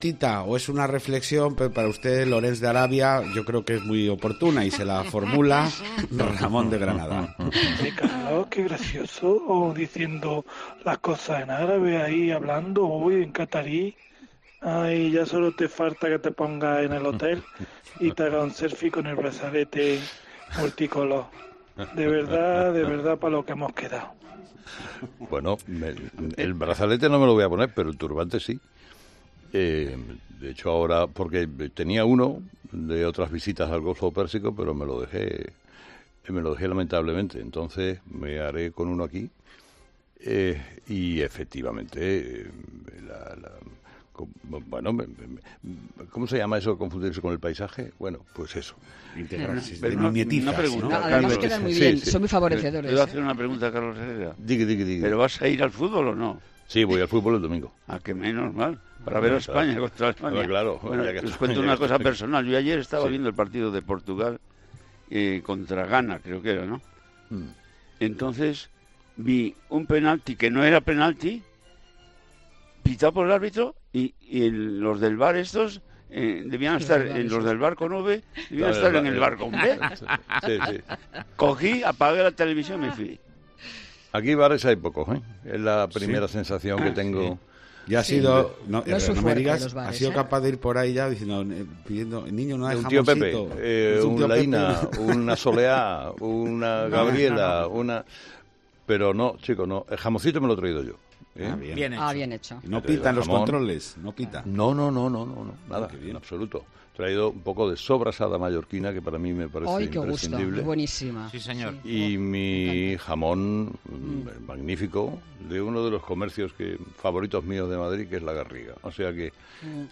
Este también ha sido el caso de Carlos Herrera, que se encuentra "en el centro de comunicación y prensa internacional".